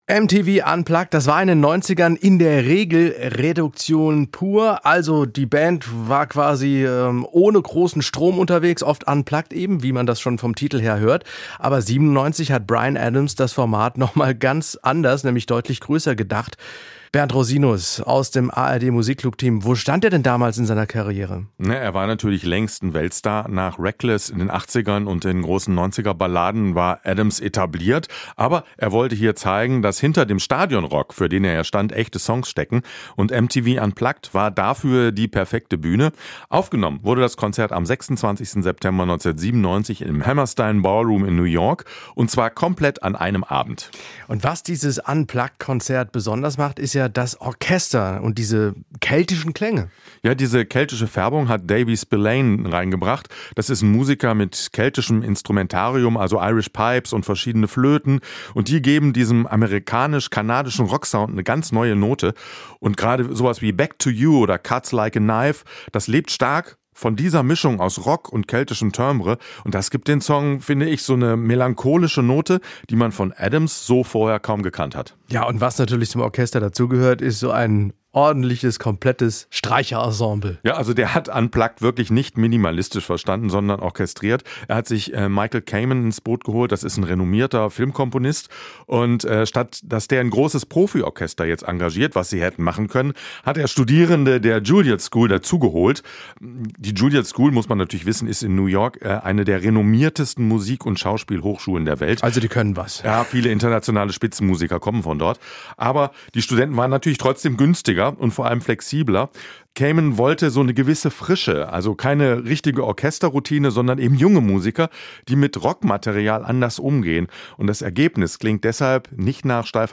Das Gespräch führte